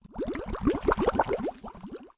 mandrake fvttdata/Data/modules/mastercrafted/assets/cauldron
bubble2.ogg